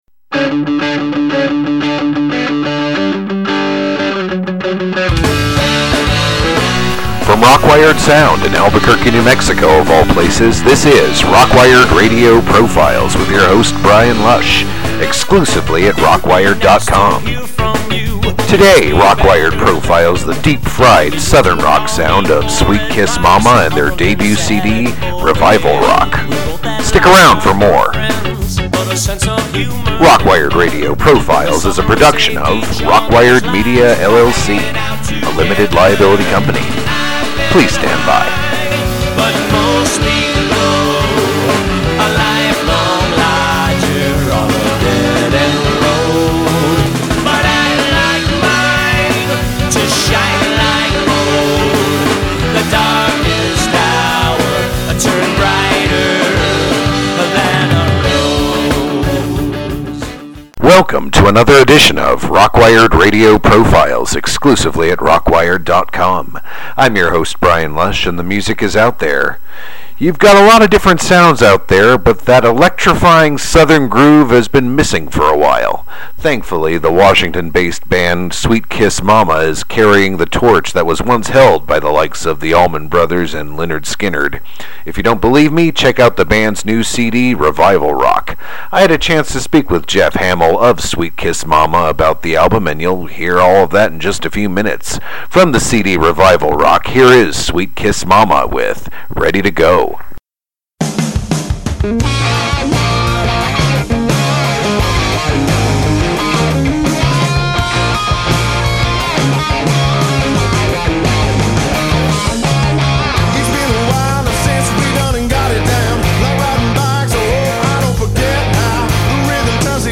The band SWEETKISS MOMMA are the torch bearers of that southern rock sound that was created by the likes of THE ALLMAN BROTHERS AND LYNYRD SKYNYRD.